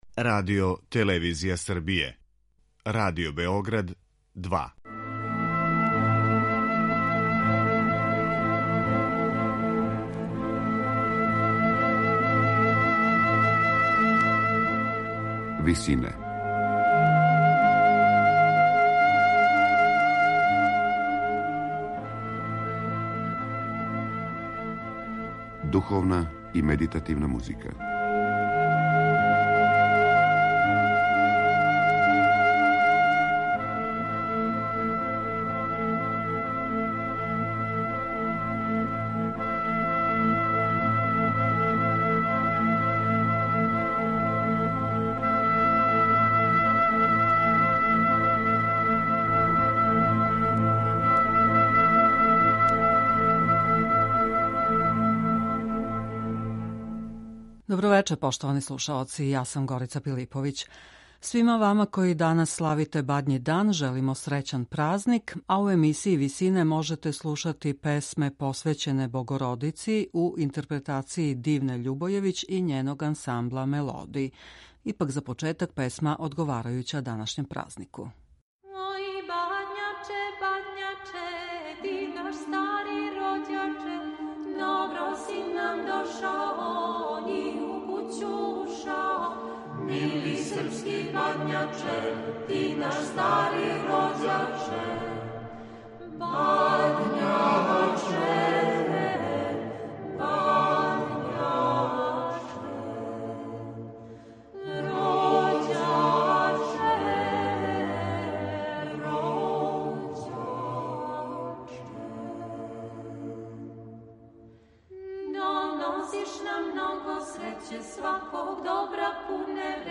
Песме посвећене Богородици